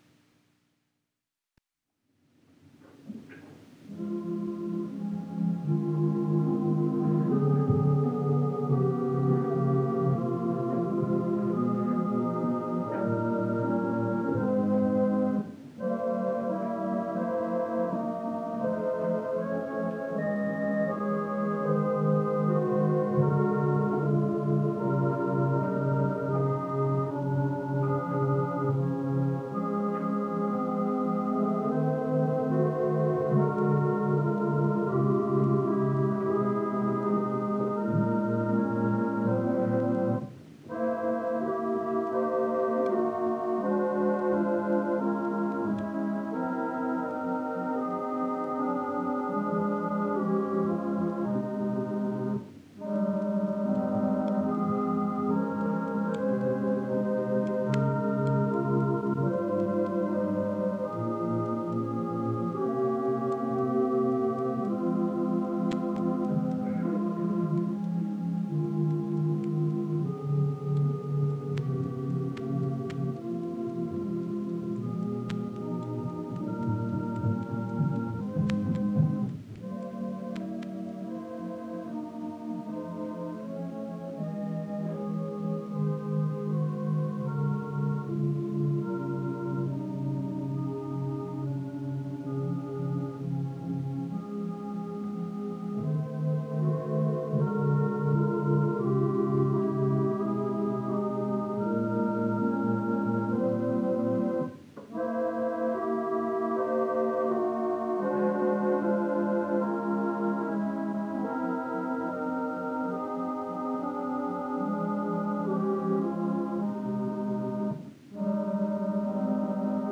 The Writer and Vedanta was recorded live at the Santa Barbara Vedanta Temple on November 11, 1960 on a wire recorder.
The archival wire version includes the introductory and concluding musical offerings that traditionally accompanied lectures at the Vedanta temples at that time. These performances were not mic'd well, and we decided not to include them on the CD; but as they have historic interest, they will be available for listening here: Opening Song and
Closing Song